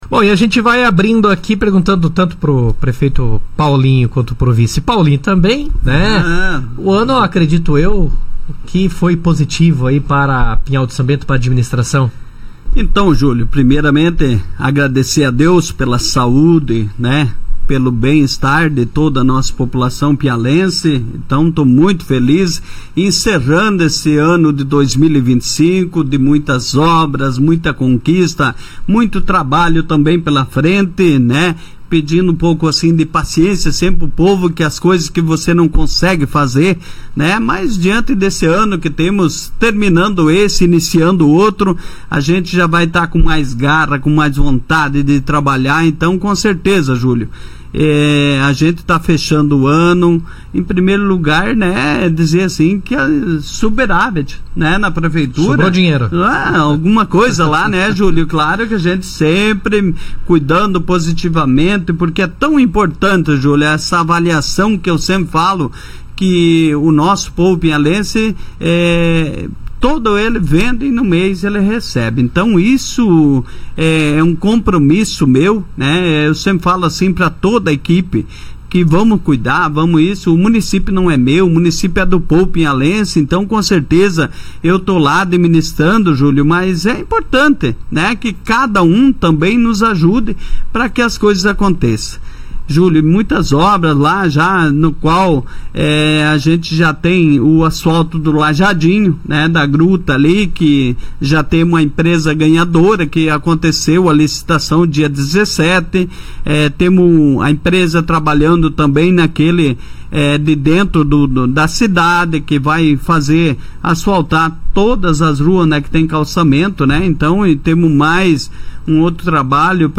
No Jornal RA 2ª Edição desta terça-feira, 30, o prefeito de Pinhal de São Bento, Paulo Falcade, e o vice-prefeito, Paulinho do Ica, participaram de uma entrevista especial para avaliar o ano de 2025 e apresentar os principais projetos previstos para 2026. Durante o diálogo, os gestores celebraram as conquistas alcançadas pelo município, destacando um superávit financeiro e um volume histórico de investimentos, que soma cerca de R$ 30 milhões aplicados em diversas áreas.